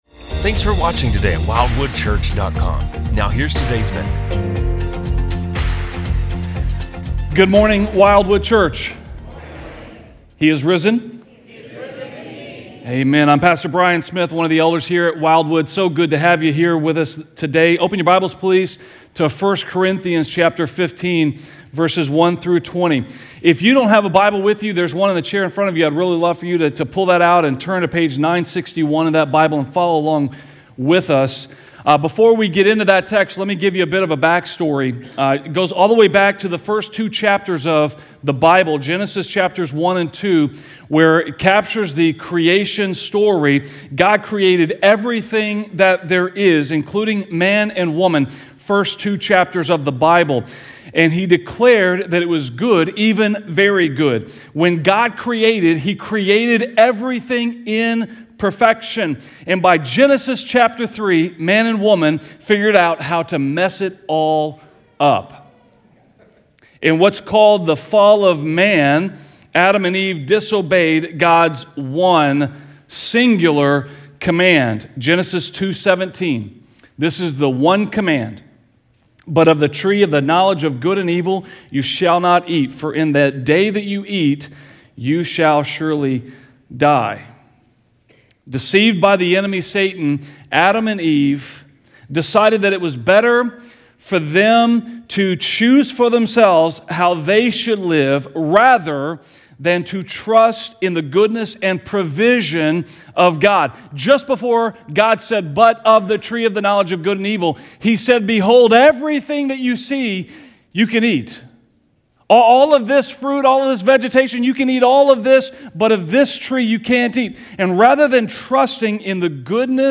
An Easter series of sermons focusing on the resurrection of Jesus.